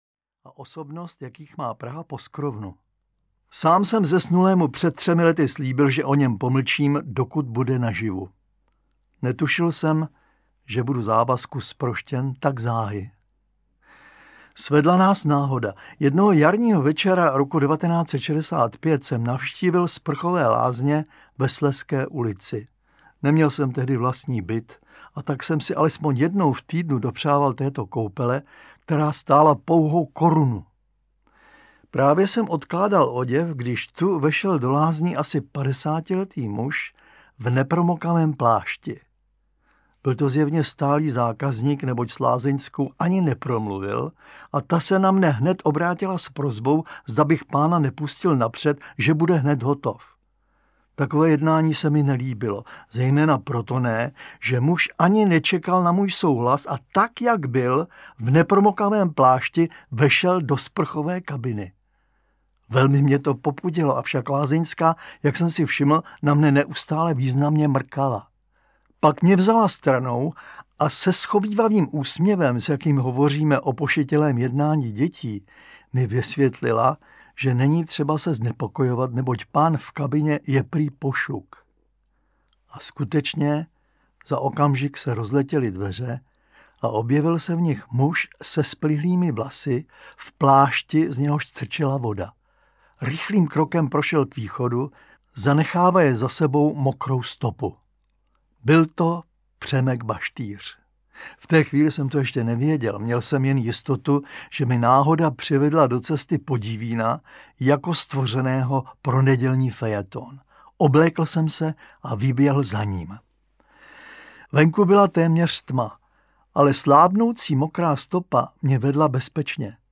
Ukázka z knihy
První 2 části shromažďují povídky, které během let Zdeněk Svěrák publikoval v knižní podobě a pak je sám načetl. Tři ze Svěrákových povídek čtou také Dana Kolářová a Libuše Šafránková.